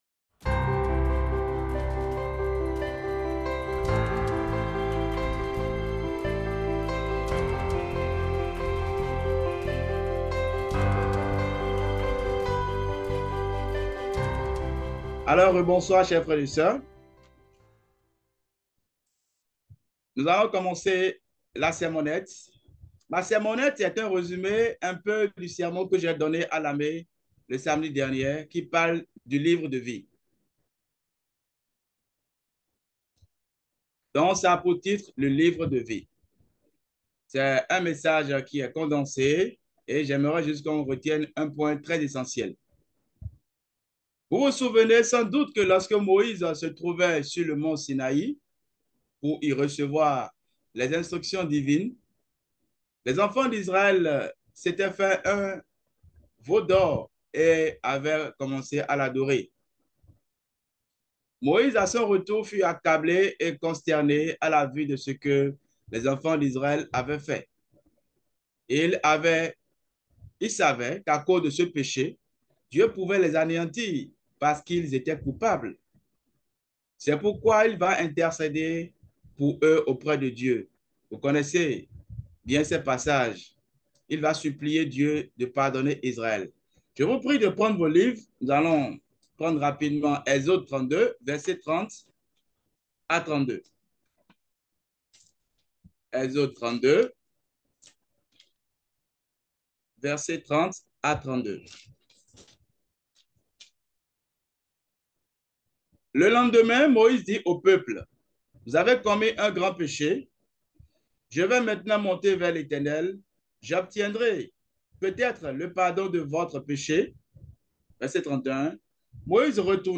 Sermons
Given in Bordeaux